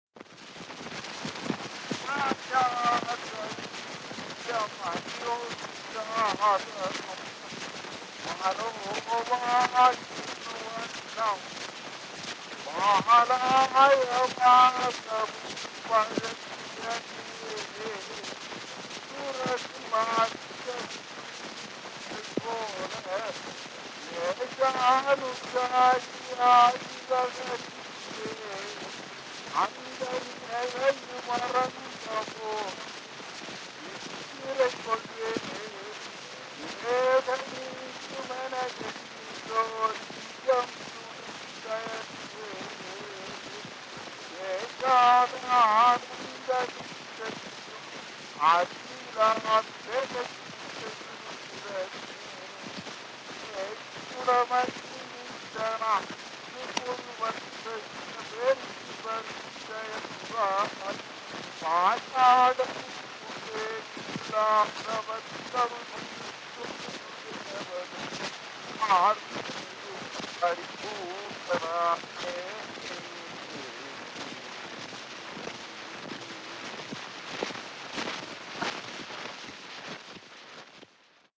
Historical sound recordings